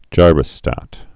(jīrə-stăt)